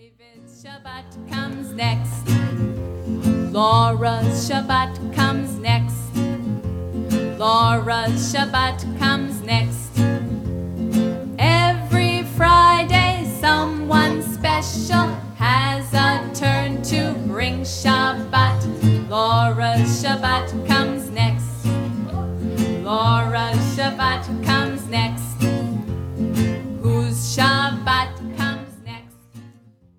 Recorded before an audience of children